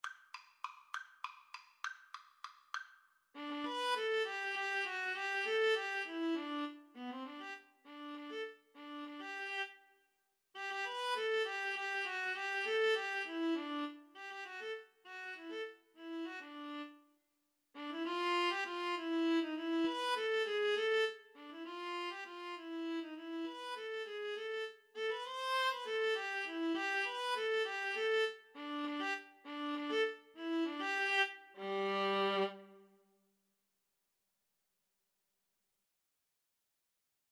3/8 (View more 3/8 Music)
Classical (View more Classical Viola Duet Music)